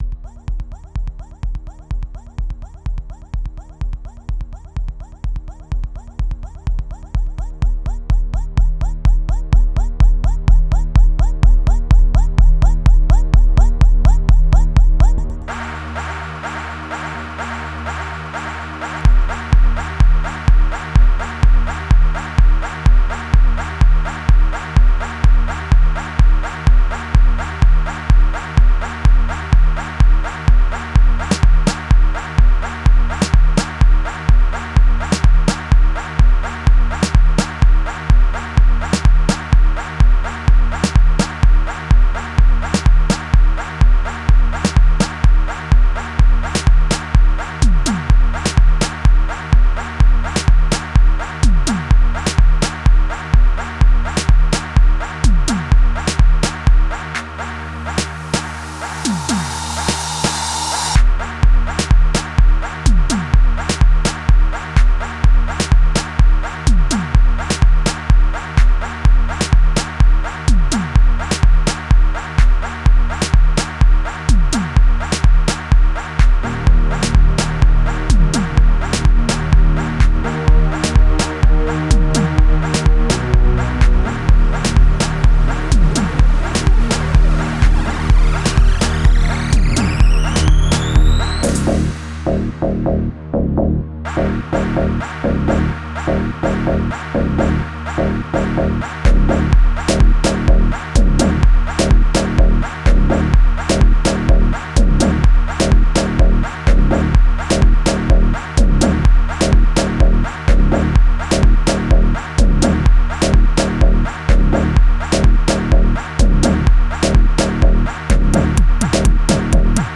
39:10 Genre : Gqom Size